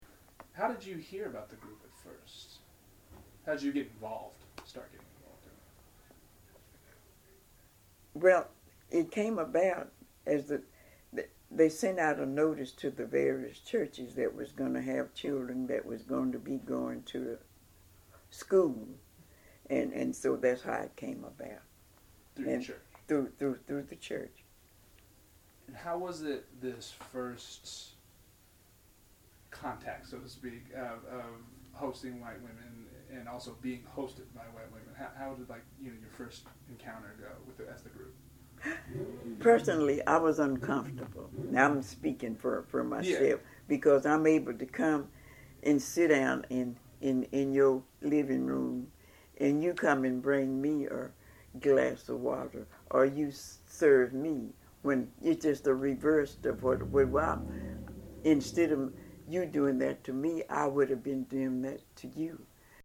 Excerpt of an Oral History Interview